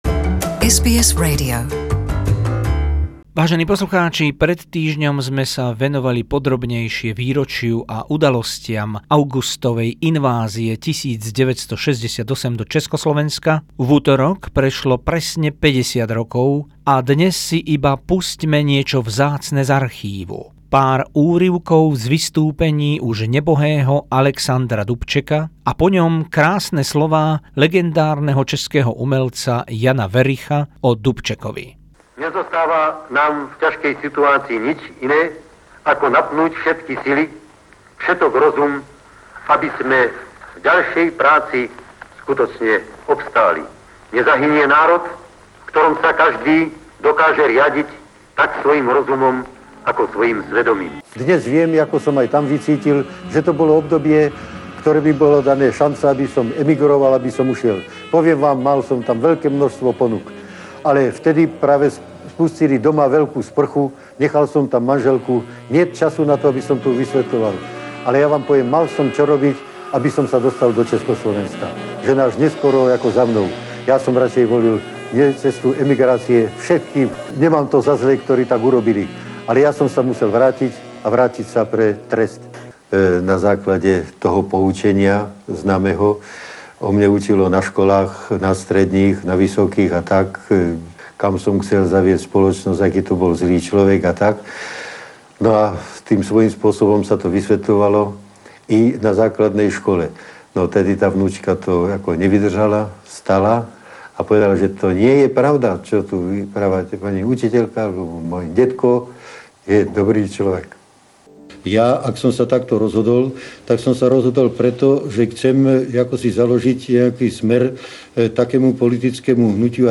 Niekoľko archívnych záznamov z prejavov hrdinu obrodenia 1968 Alexandra Dubčeka a názor legendárneho herca Jana Wericha na Dubčeka.